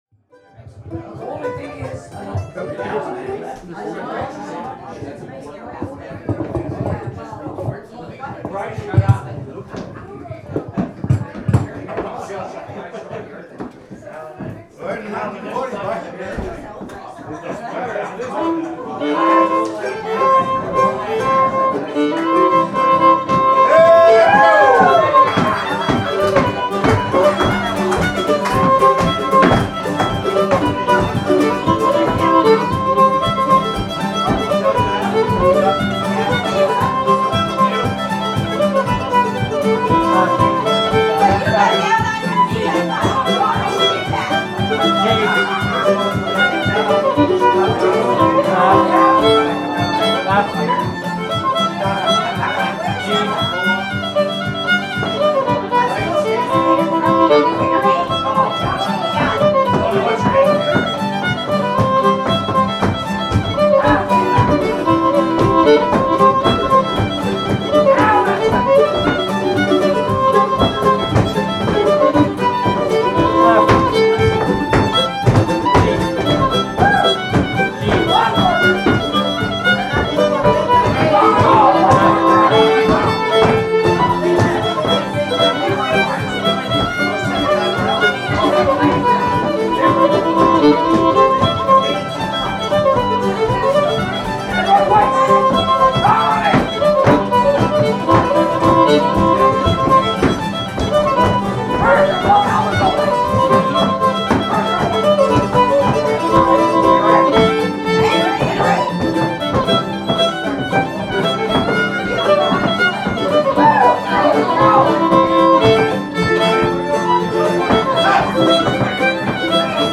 House Party
fiddle
mandolin
guitar
This selection is a short segment of us playing for a long square dance
17-clyde-dursts-tune-square-dance-segment.mp3